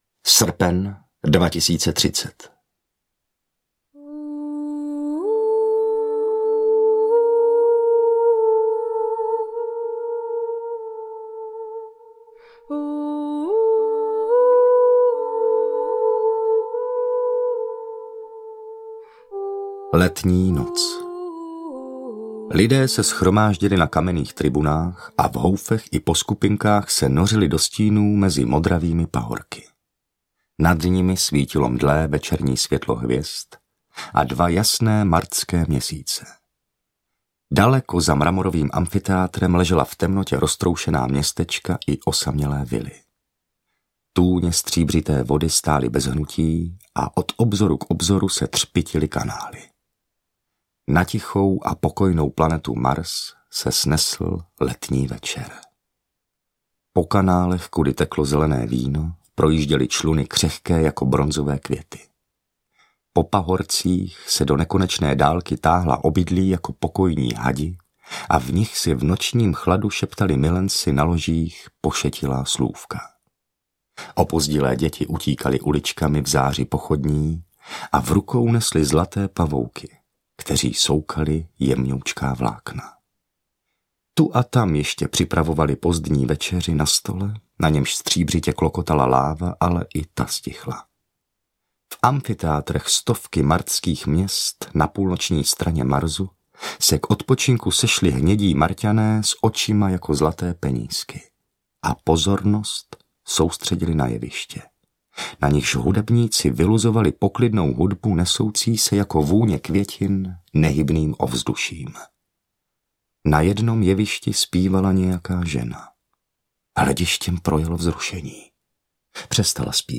Marťanská kronika audiokniha
Ukázka z knihy
martanska-kronika-audiokniha